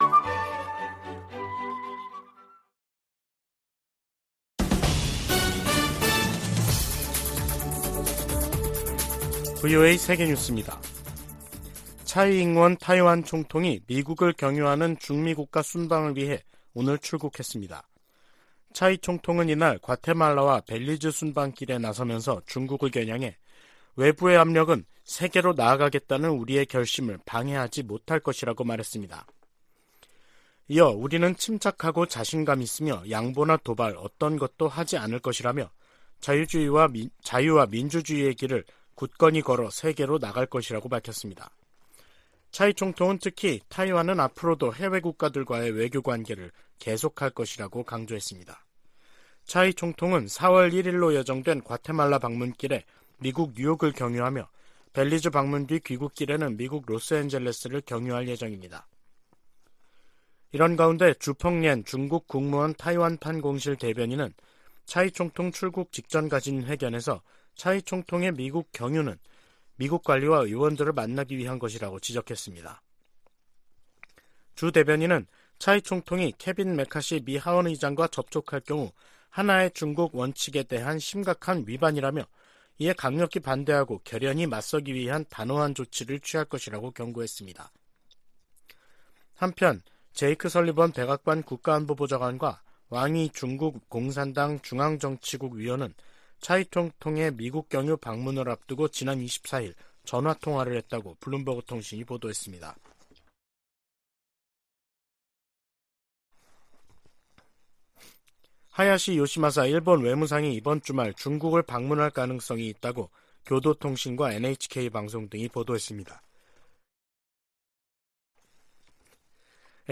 VOA 한국어 간판 뉴스 프로그램 '뉴스 투데이', 2023년 3월 29일 2부 방송입니다. 백악관은 북한의 전술핵탄두 공개에 국가 안보와 동맹의 보호를 위한 준비태세의 중요성을 강조했습니다. 국무부는 북한의 '핵 공중폭발 시험' 주장에 불안정을 야기하는 도발행위라고 비난했습니다. 전문가들은 북한이 핵탄두 소형화에 진전을 이룬 것으로 평가하면서 위력 확인을 위한 추가 실험 가능성이 있다고 내다봤습니다.